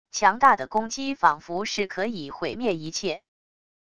强大的攻击仿佛是可以毁灭一切wav音频